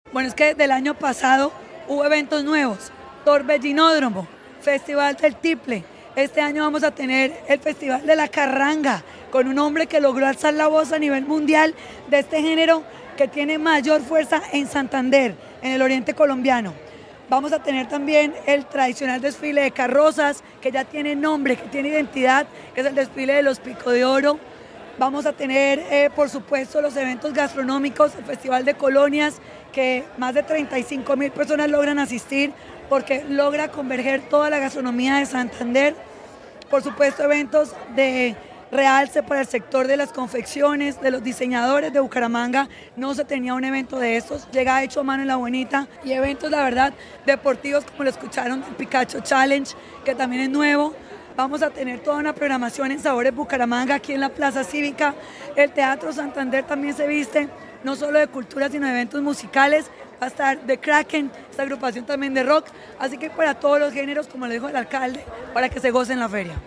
Laura Patiño, directora del Instituto Municipal de Cultura y Turismo